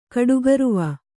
♪ kaḍugaruva